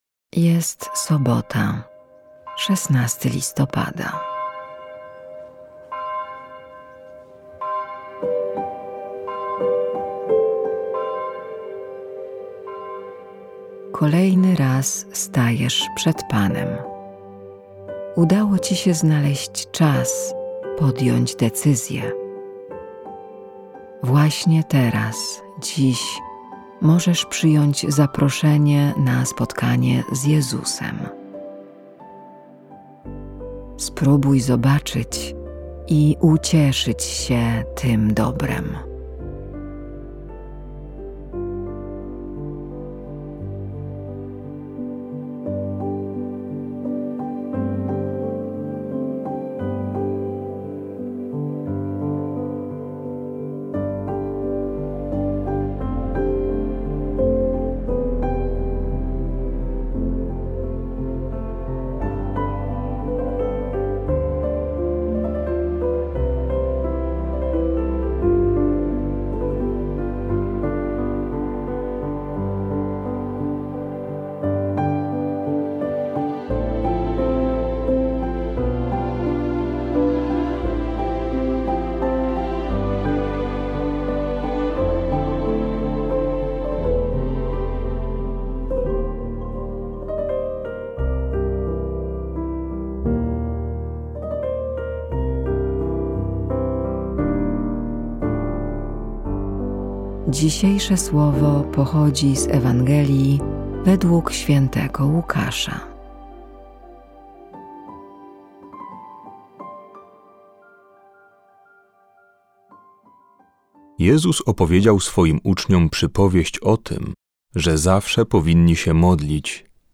Polscy Jezuici, idąc za przykładem swoich braci z Wielkiej Brytanii, zaproponowali serie około dziesięciominutowych rozważań łączących muzykę i wersety z Biblii, pomagające odkrywać w życiu Bożą obecność i pogłębić relacje z Bogiem.